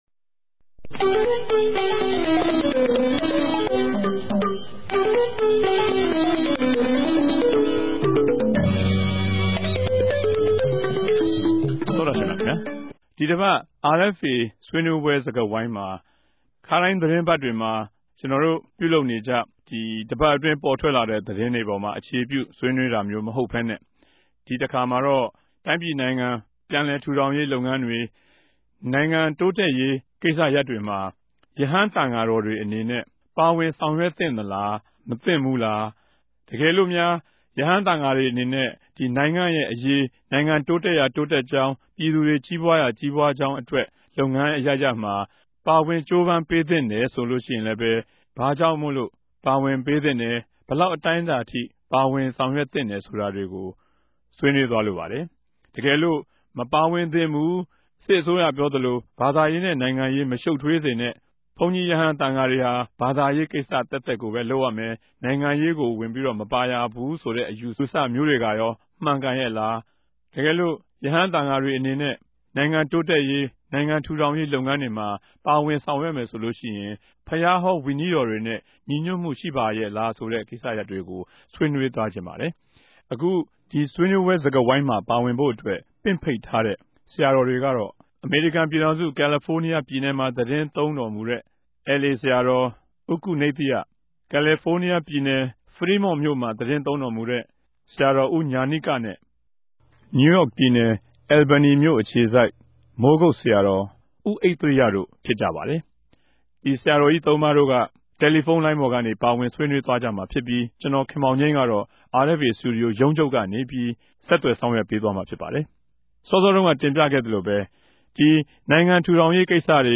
တပတ်အတြင်းသတင်းသုံးသပ်ခဵက် စကားဝိုင်း (၂၀၀၆ အောက်တိုဘာလ ၇ရက်)
တယ်လီဖုန်းနဲႛ ဆက်သြယ် မေးူမန်း္ဘပီး တင်ဆက်ထားတာကို နားထောငိံိုင်ပၝတယ်။